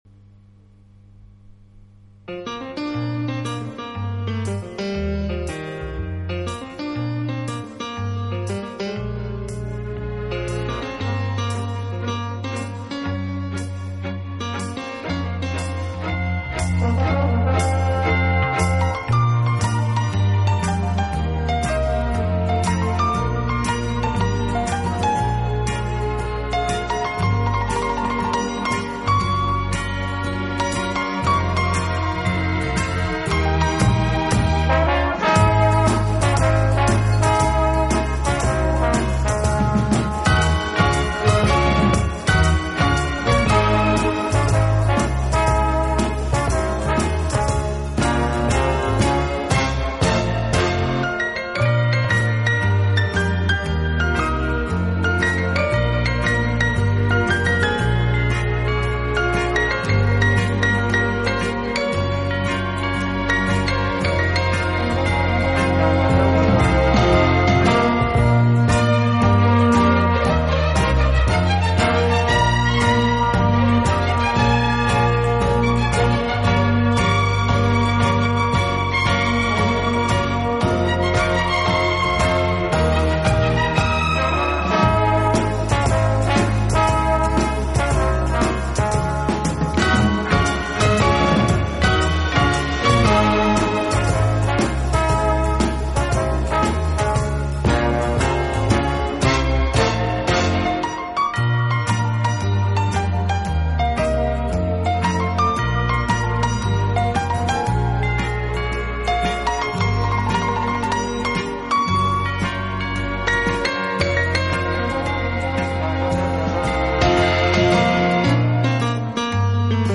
方式，尤其是人声唱颂的背景部分，似乎是屡试不爽的良药。
有动感，更有层次感；既有激情，更有浪漫。